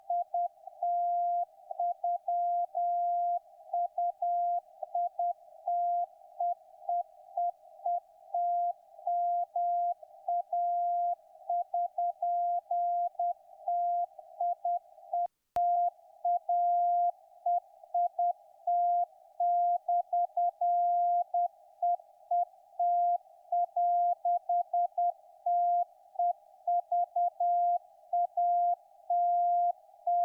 Für den Vergleich habe ich Audioaufnahmen der beiden Empfänger gemacht.
Sekunde 0-15 >> SDRplay RSPduo
Sekunde 15-30 >> Winradio G33DDC Excalibur Pro
CW